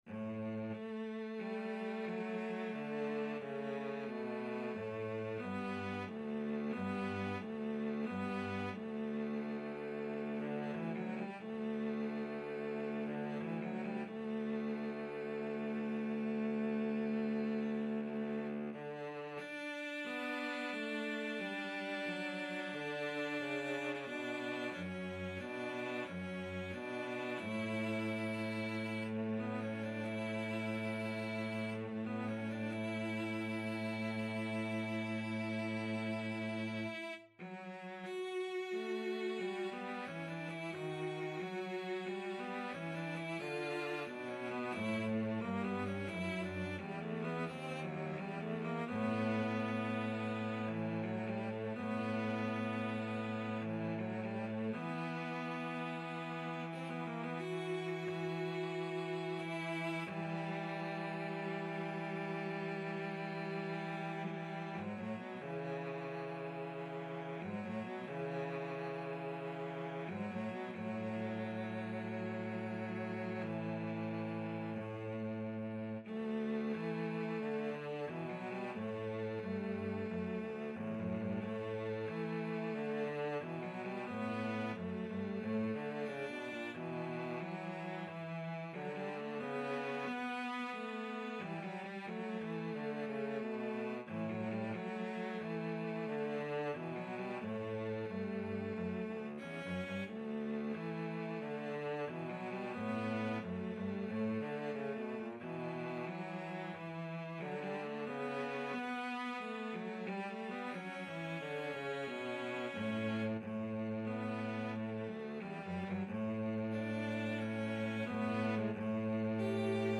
4/4 (View more 4/4 Music)
Ruhig bewegt = c. 90
Classical (View more Classical Cello Duet Music)